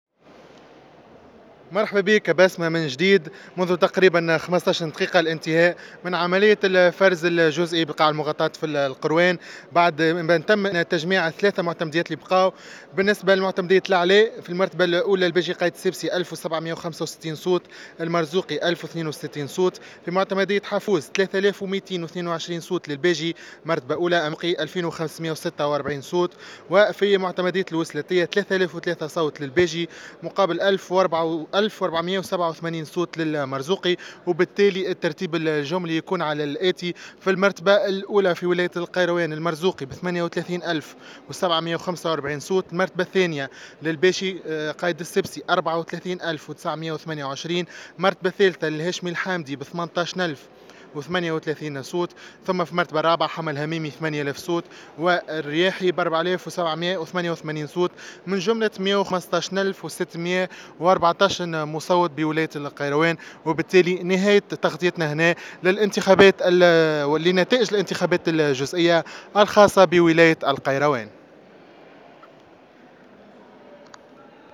مراسلنا بالقيروان